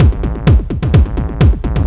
DS 128-BPM B8.wav